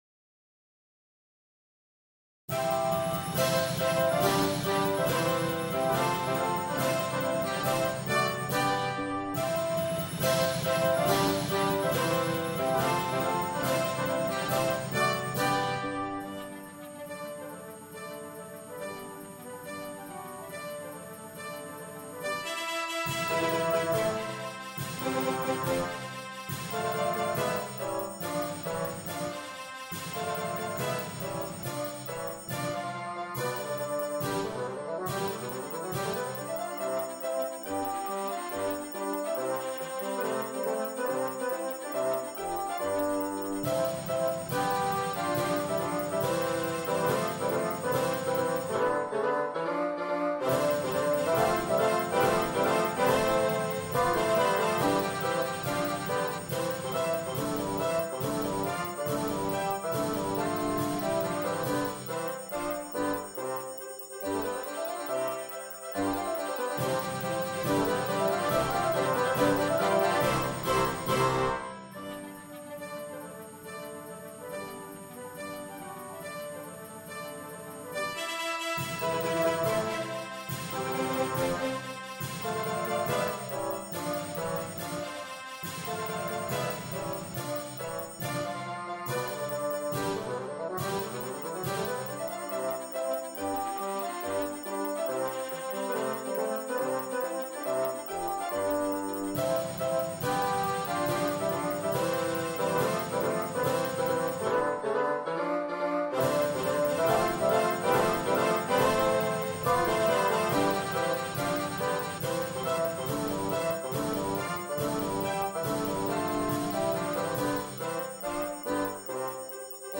Marche militaire en do majeur pour fanfare